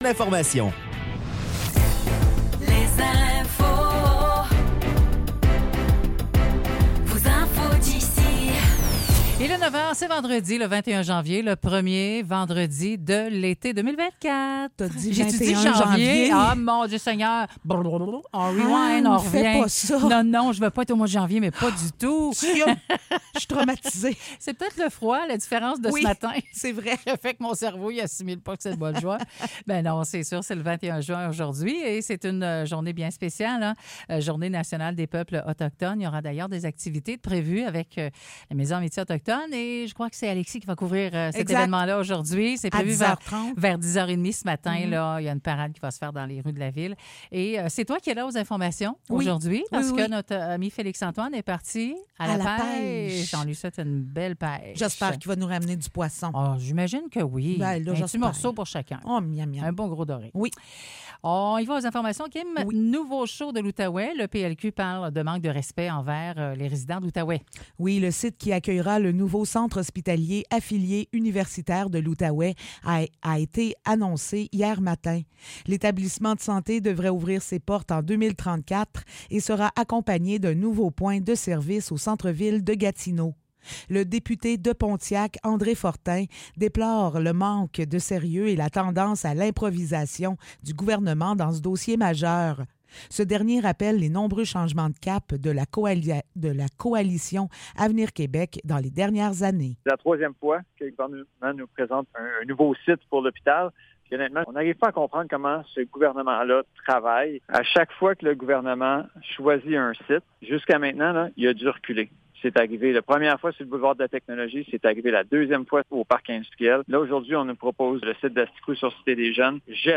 Nouvelles locales - 21 juin 2024 - 9 h